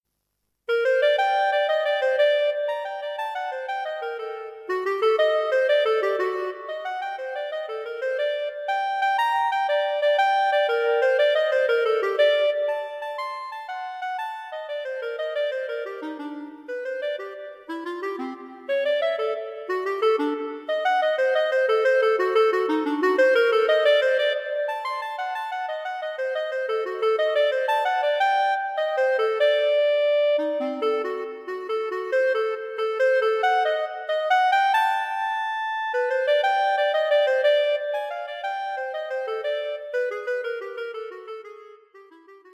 Unaccompanied Clarinet Solos.